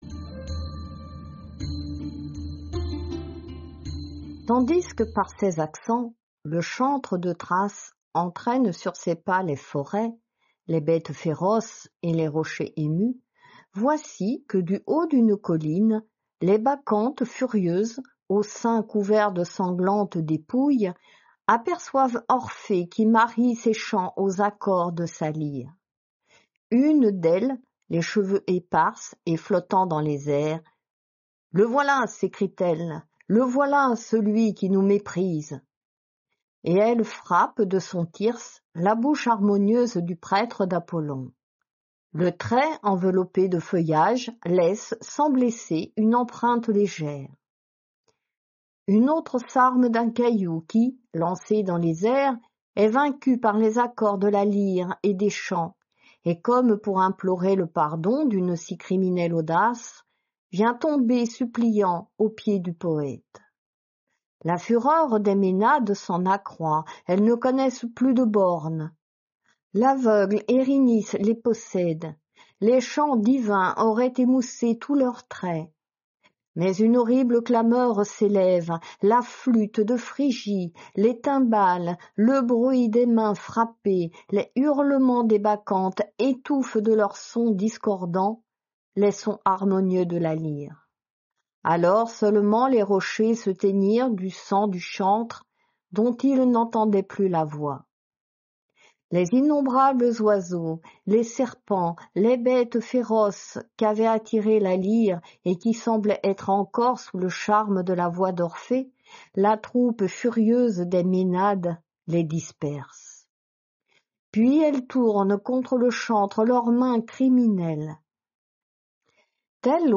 Lecture de la métamorphose des Ménades · GPC Groupe 1